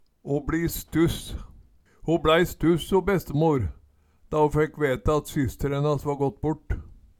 å bLi stuss - Numedalsmål (en-US)